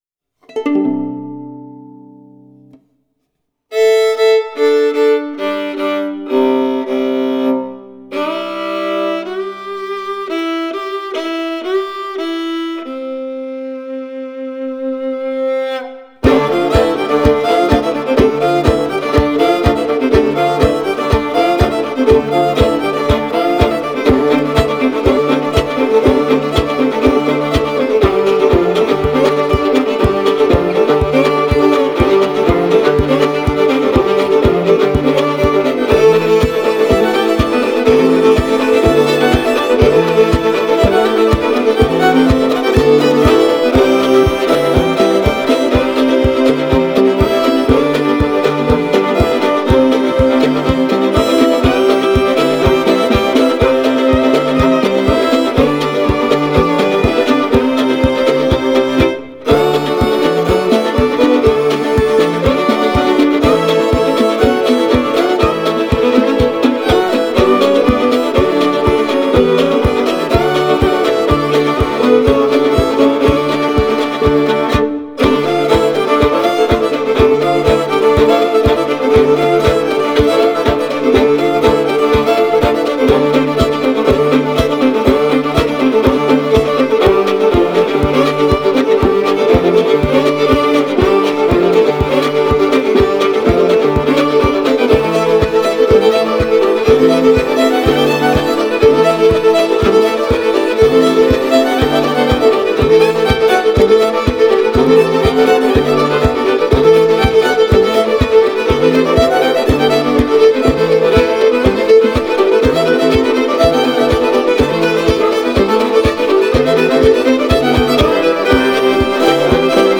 Having played the fiddle from an early age